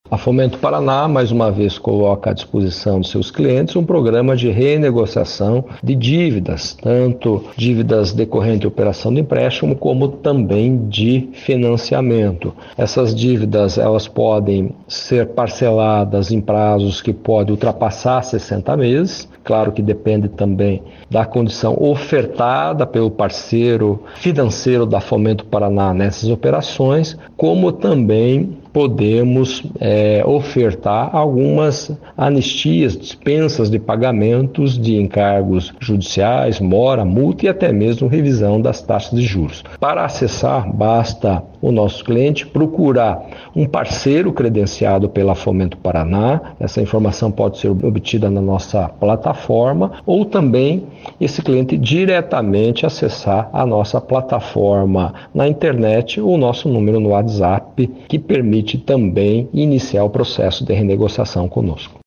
Sonora do diretor-presidente da Fomento Paraná, Heraldo Neves, sobre a nova campanha da instituição para renegociação de contratos